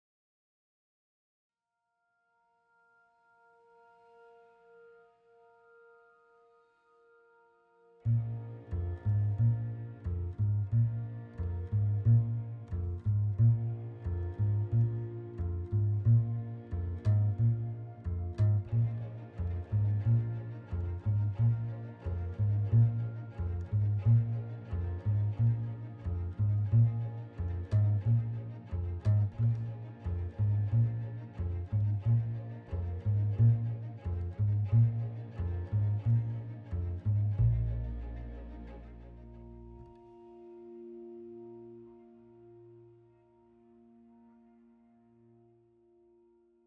the score for the documentary